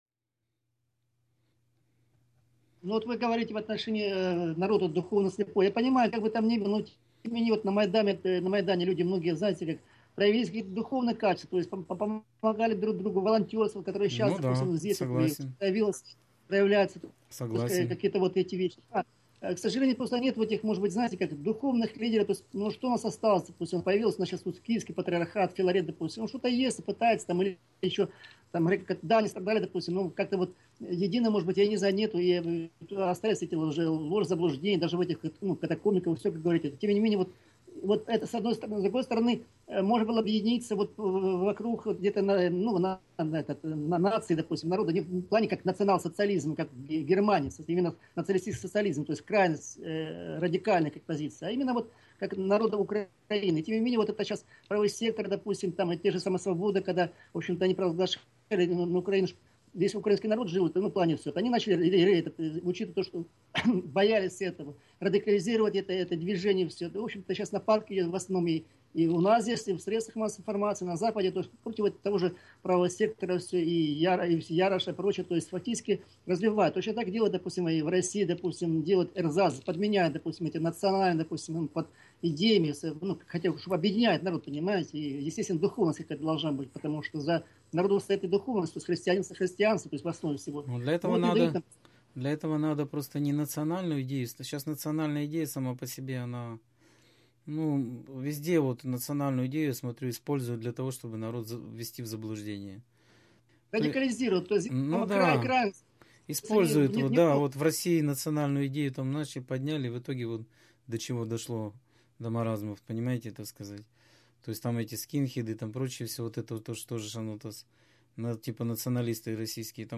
Скайп-беседа 23.01.2016